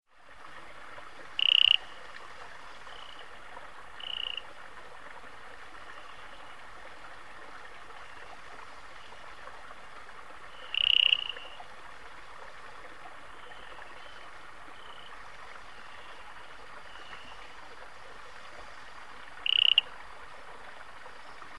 Play Especie: Oreobates cruralis Género: Oreobates Familia: Strabomantidae Órden: Anura Clase: Amphibia Título: Guía sonora de las ranas y sapos de Bolivia.
Localidad: Bolivia: Mataracú, Parque Nacional Amboró / Mataracú, Amboró National Park
37 Eleutherodactylus Cruralis.mp3